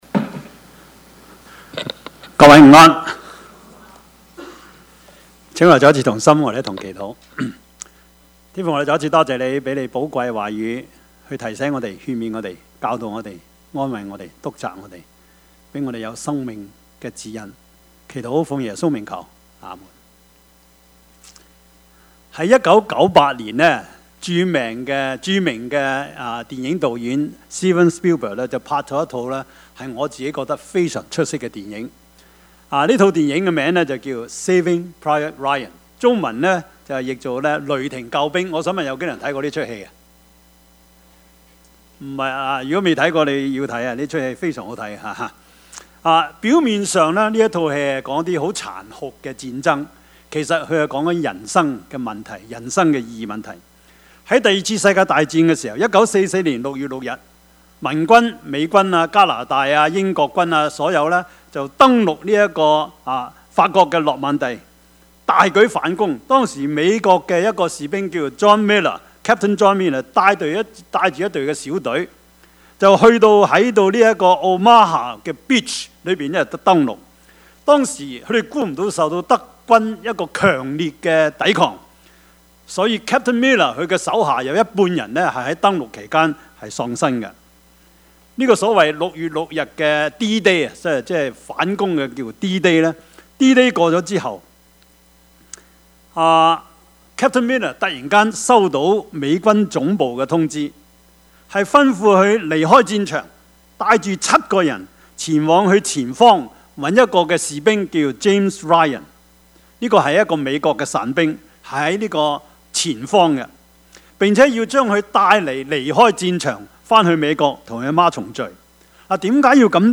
Passage: 路加福音 22:7-23 Service Type: 主日崇拜
Topics: 主日證道 « 撒但入了他的心 新年願望 »